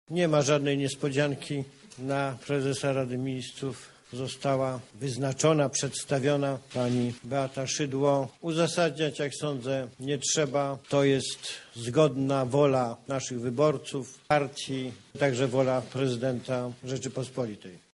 – tłumaczy Jarosław Kaczyński, prezes Prawa i Sprawiedliwości.